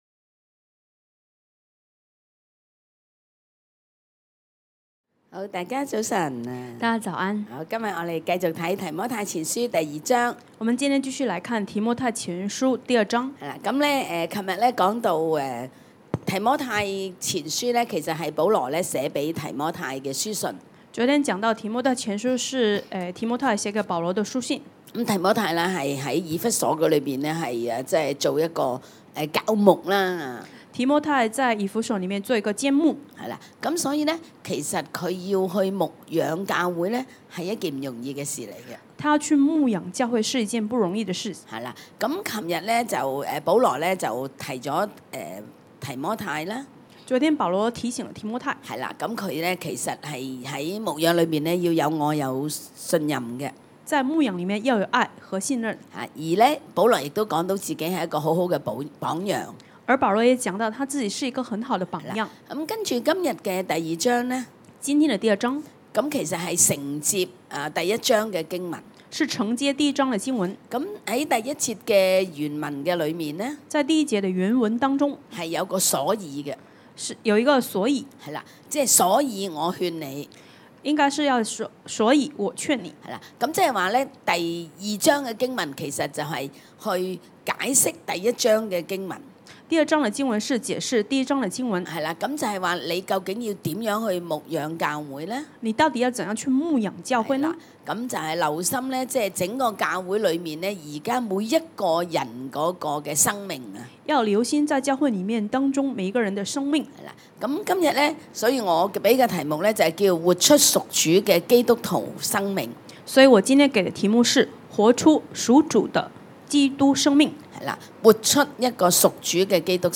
現場的肢體為弟兄禱告，祝福他們能舉起聖潔的手，隨處禱告。